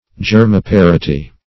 Search Result for " germiparity" : The Collaborative International Dictionary of English v.0.48: Germiparity \Ger`mi*par"i*ty\, n. [Germ + L. parere to produce.]